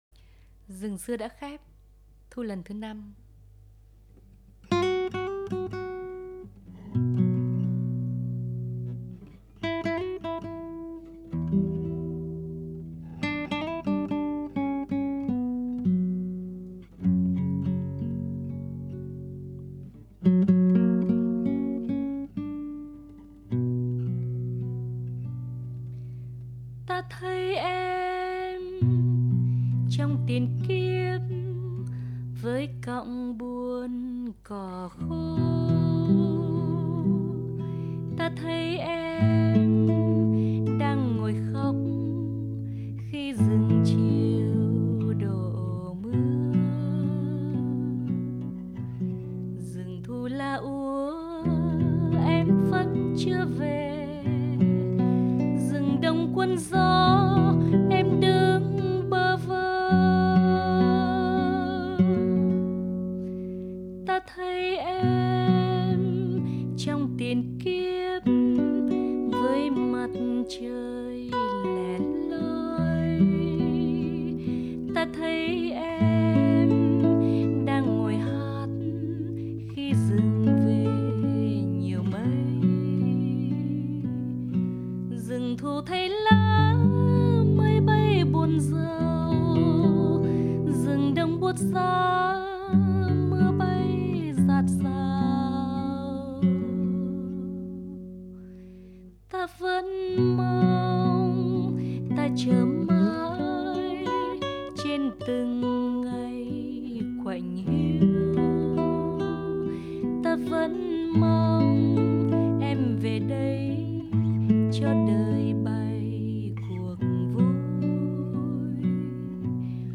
a gorgeous Vietnamese song